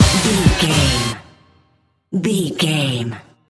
Fast paced
Ionian/Major
D
Fast
synthesiser
drum machine